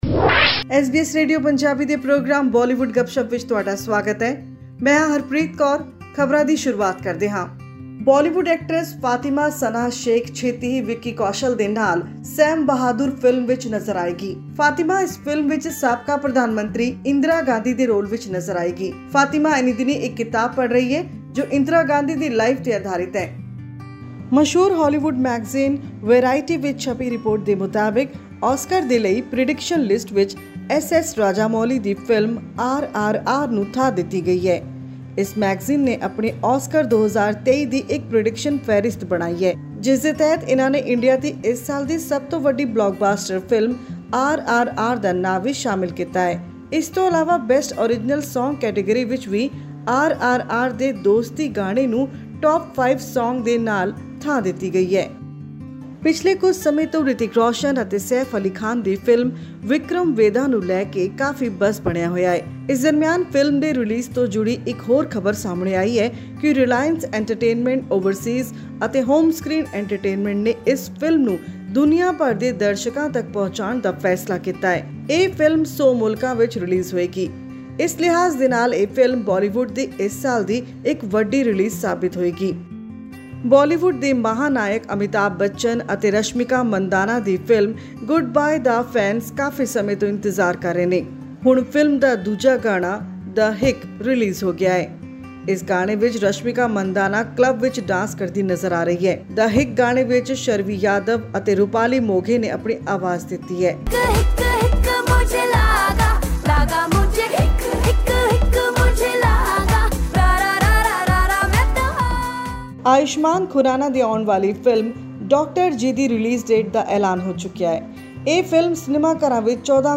Punjab and Haryana High Court has granted bail to singer Daler Mehndi, who was sentenced to two years in prison in a 2003 human trafficking case. He was found guilty of illegally sending people abroad disguised as members of his music troupe and was imprisoned in Patiala jail. For more information, listen to our weekly news bulletin from Bollywood.